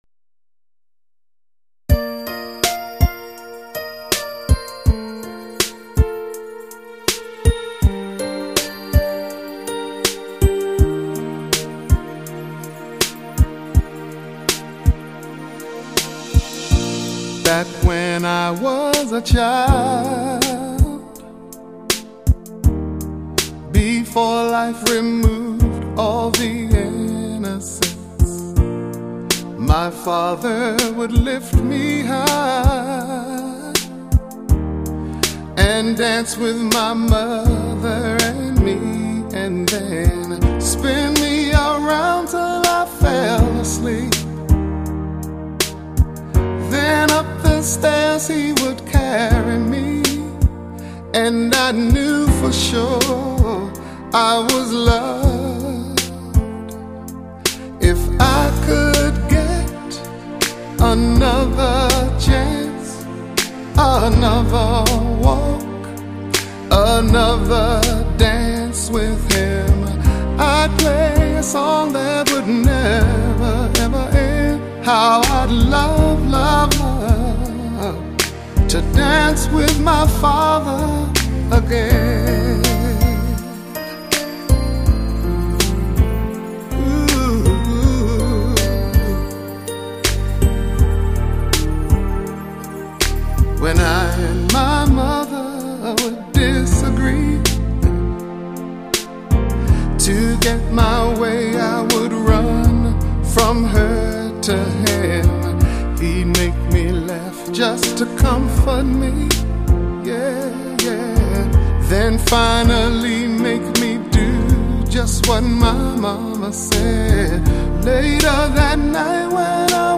极致的发烧录音24Bit 高解析录音
日本JVC特别低音处理，试听时注意调节BASS（低音）